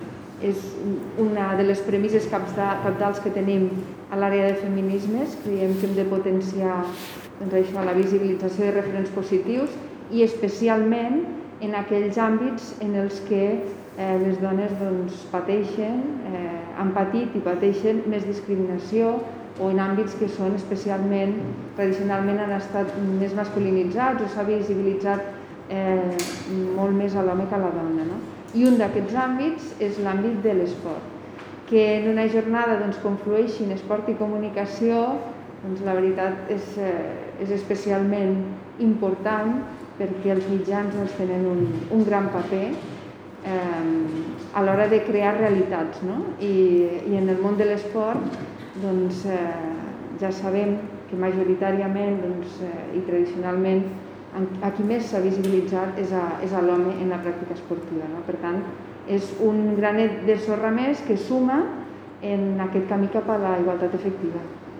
tall-de-veu-de-la-tinent-dalcalde-sandra-castro-sobre-les-ii-jornades-comunicacio-dona-i-esports-ellas-son-de-aqui